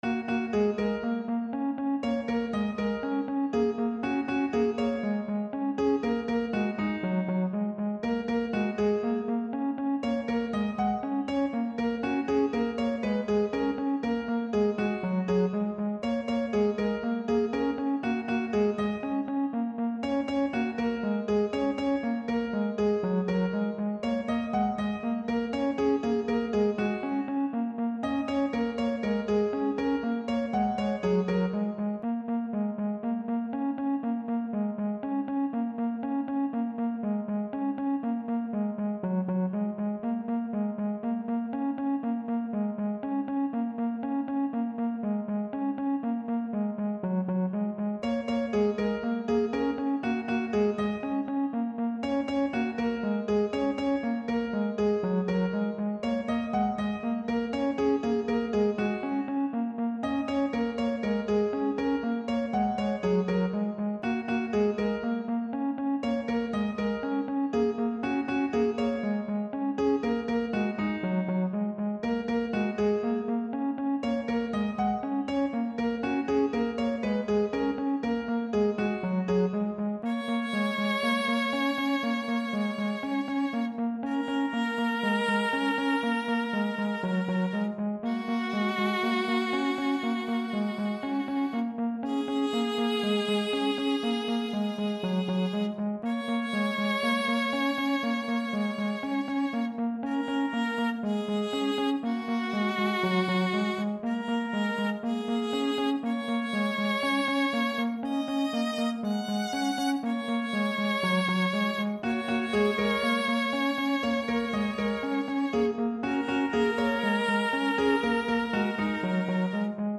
bittersweet
melancholy
piano
violin
relaxing
calm
v1.0 - "Thinner" original version